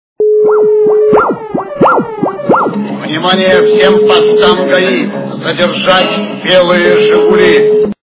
» Звуки » Из фильмов и телепередач » Вой полицейской сирены - Внимание, всем постам ГАИ!
При прослушивании Вой полицейской сирены - Внимание, всем постам ГАИ! качество понижено и присутствуют гудки.